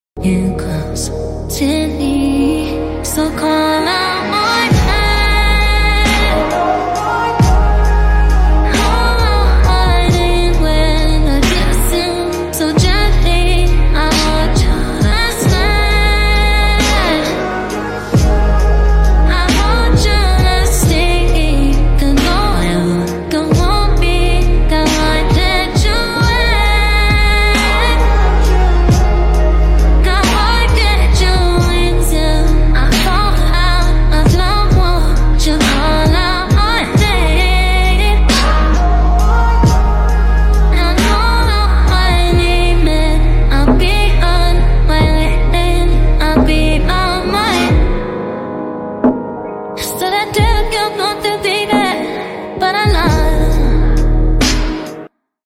AI Cover!